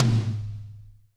-DRY TOM 2-R.wav